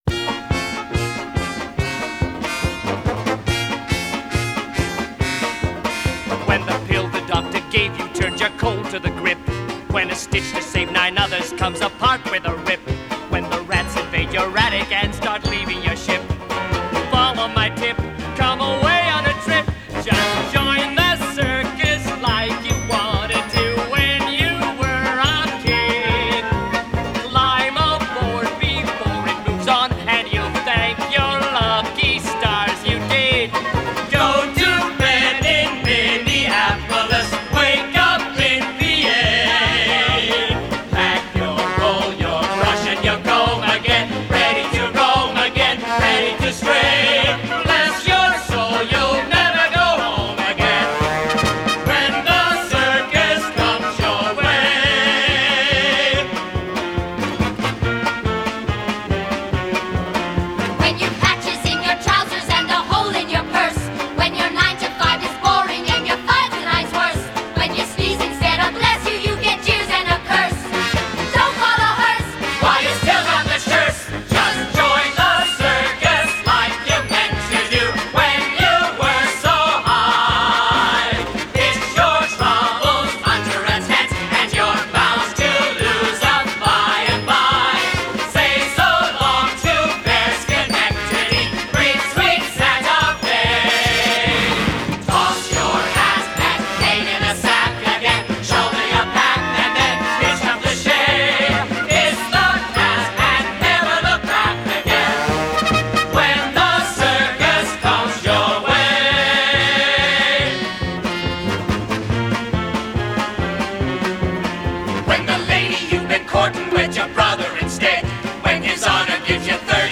1980   Genre: Musical   Artist